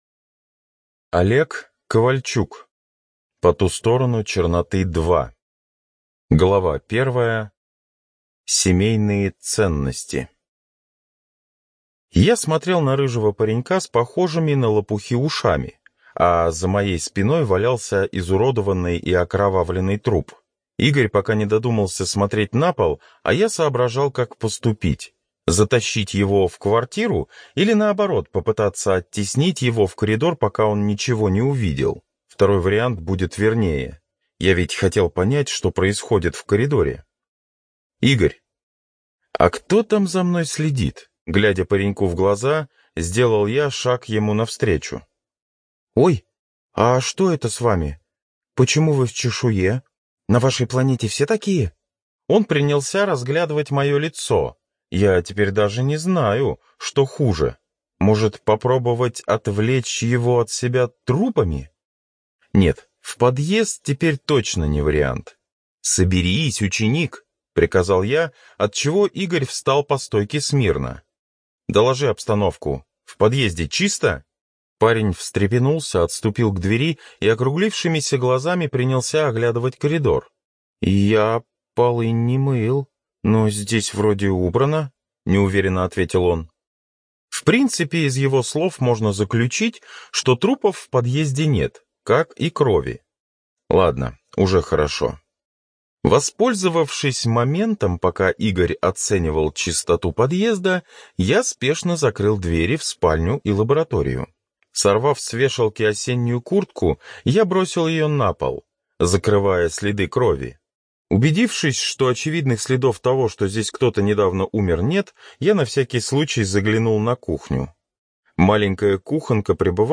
ЖанрФэнтези